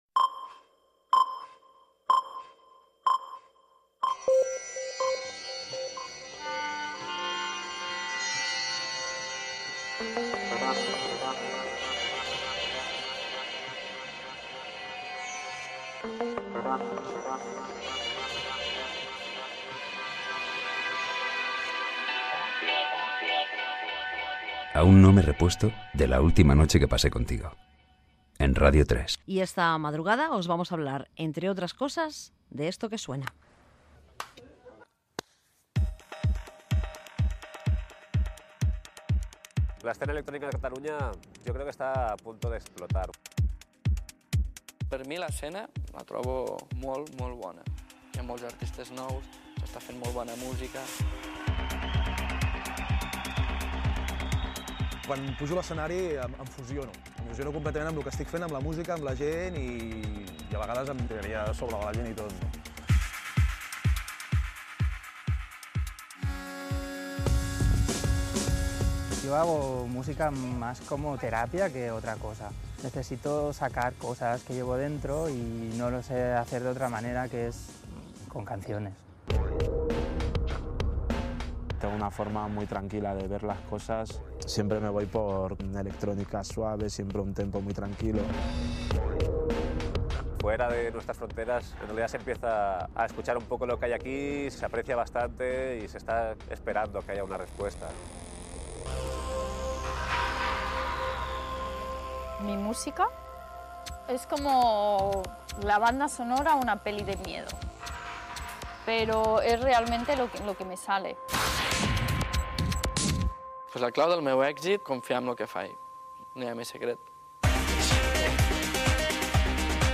Careta del programa, l'escena de la música electrònic a Catalunya: el documental "Sueños rotos", amb declaracions de diveros músics
Musical